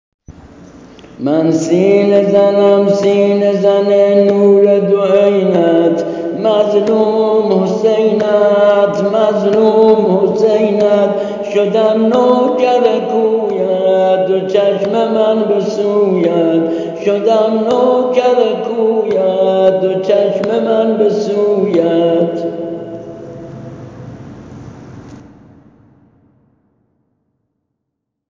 ◾نوحه‌ی‌‌سینه زنی
◾درقالب شور زمینه‌و‌نوحه ایستاده
◾سبک سنتی